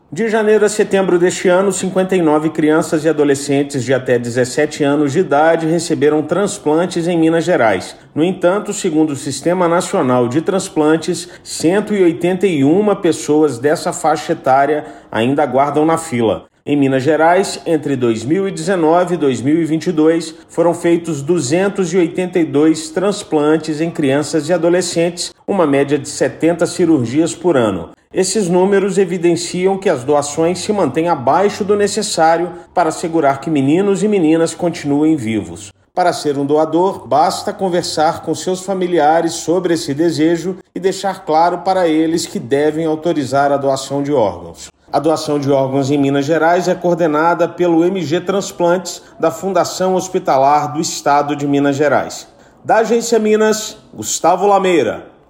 As doações continuam abaixo do necessário para assegurar que meninas e meninos possam continuar vivos. Ouça matéria de rádio.